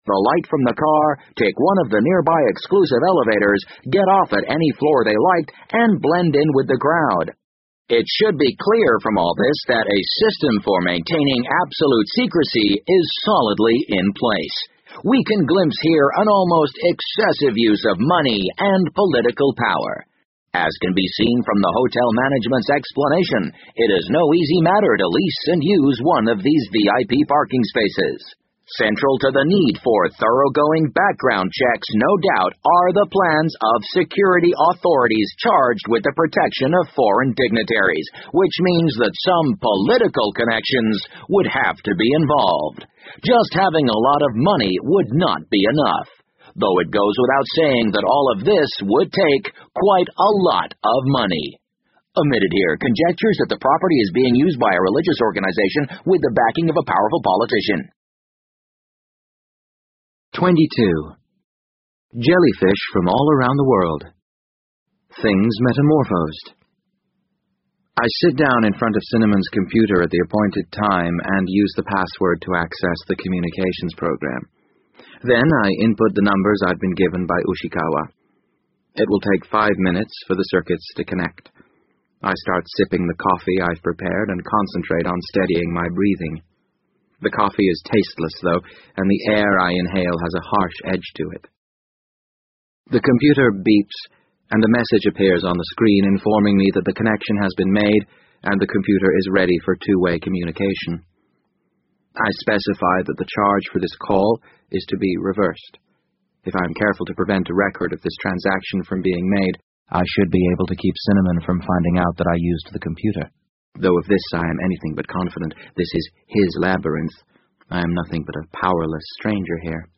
BBC英文广播剧在线听 The Wind Up Bird 012 - 15 听力文件下载—在线英语听力室